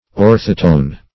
Search Result for " orthotone" : The Collaborative International Dictionary of English v.0.48: Orthotone \Or"tho*tone\, a. [Ortho- + Gr.